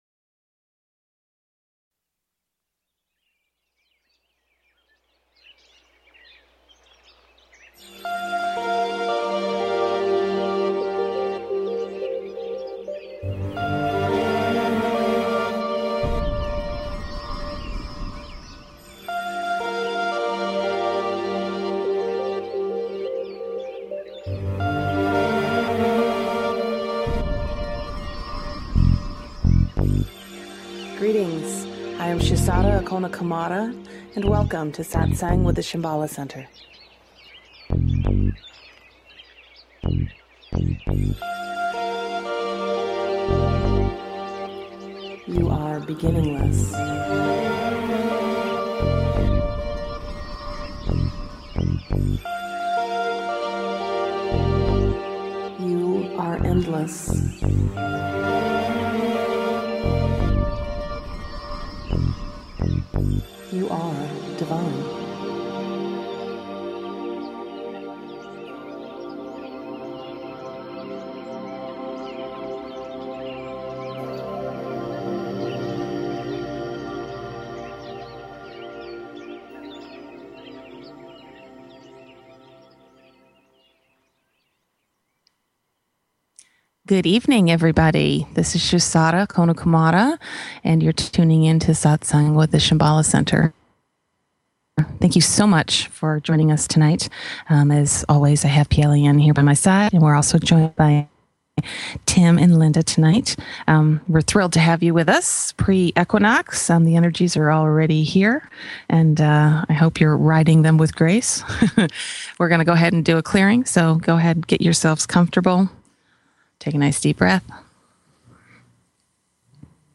Talk Show Episode
The guide facilitates the session by holding the energy for the group, providing teachings, answering questions and facilitating meditations.